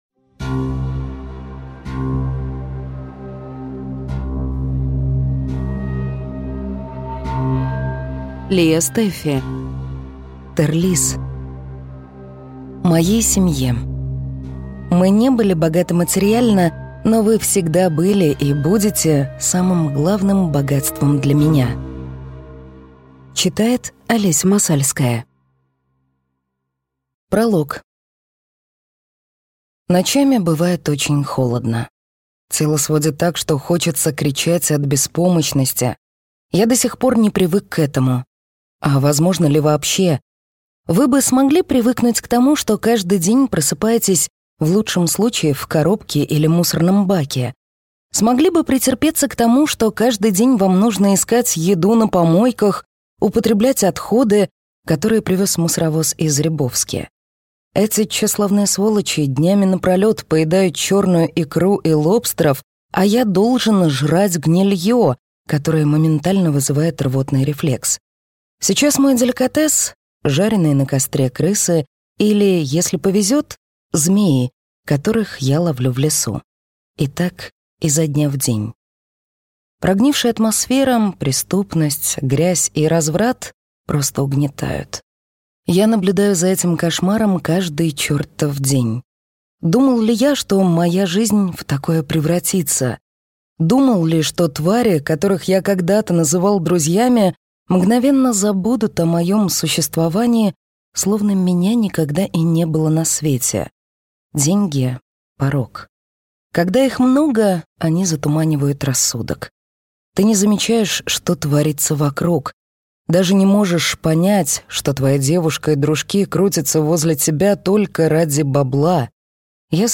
Аудиокнига Тэррлисс | Библиотека аудиокниг